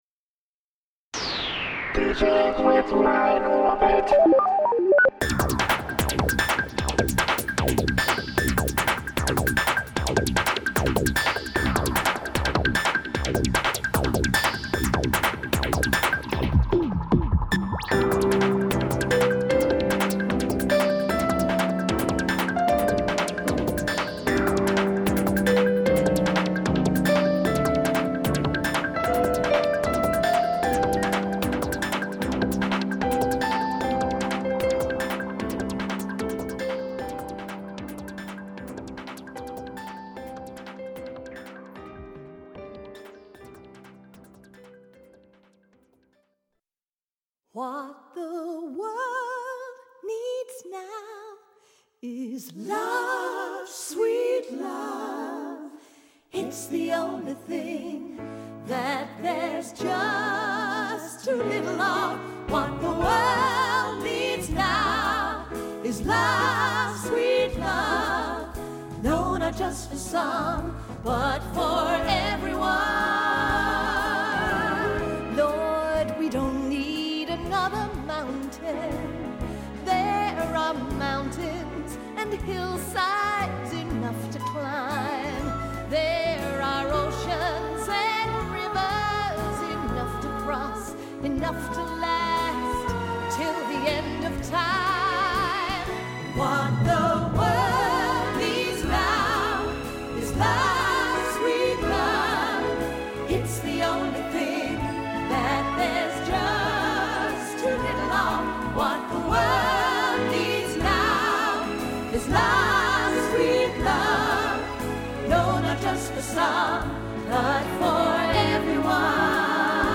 for vocal ensemble and orchestra